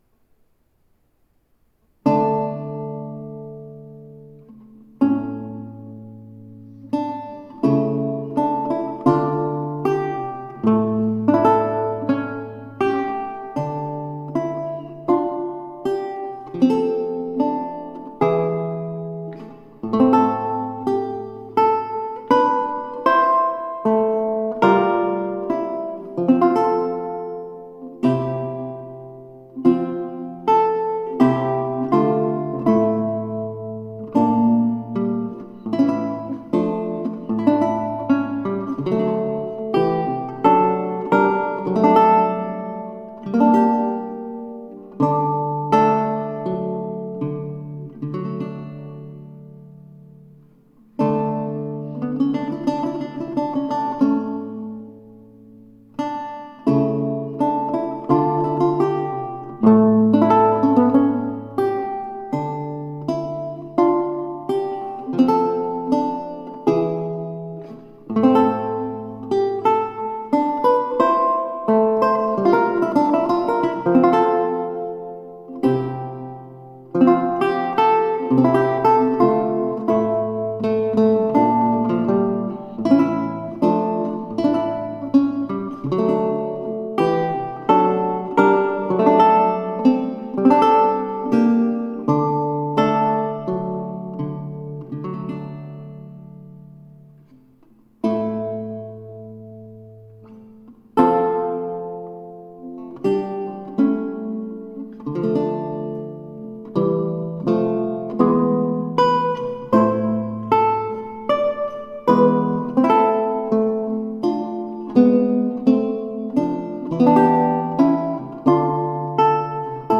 Virtuoso english lutenist and guitarist.
Recorded in St. Bartholomew's Church, Otford.
Classical, Renaissance, Instrumental